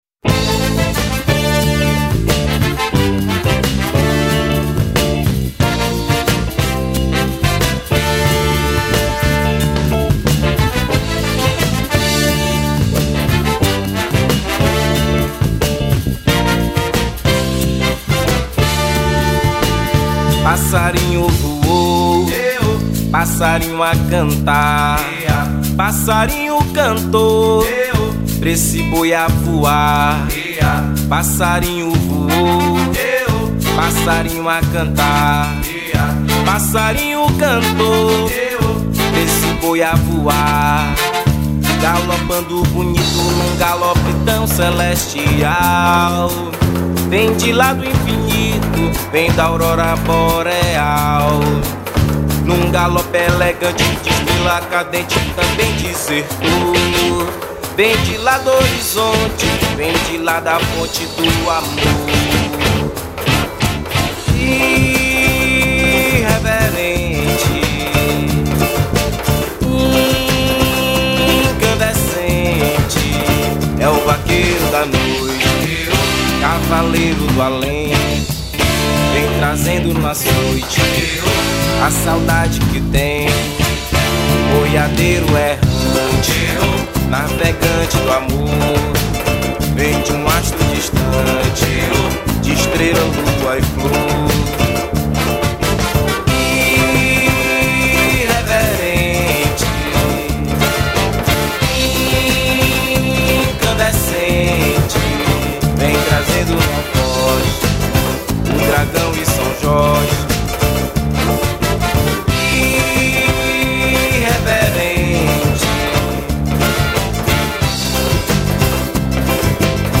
04:24:00   Boi Bumbá